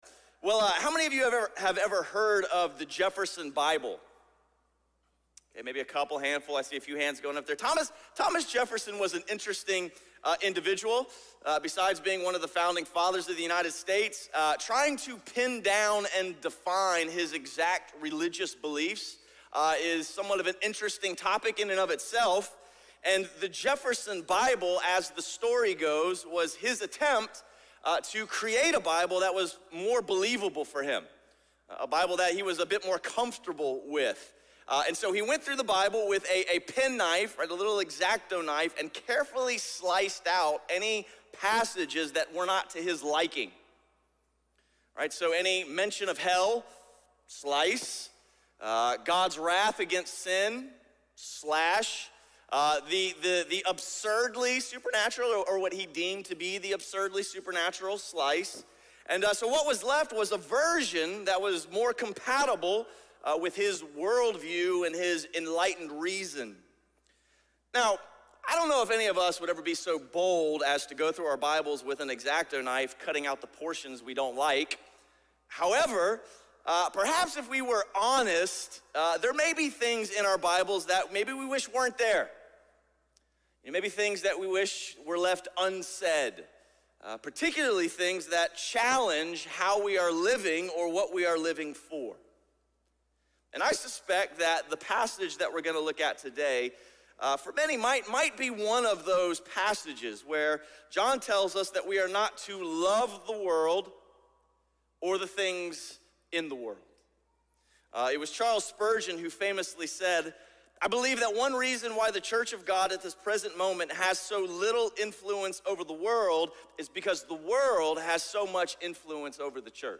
A message from the series "Judges: {Un} Faithful."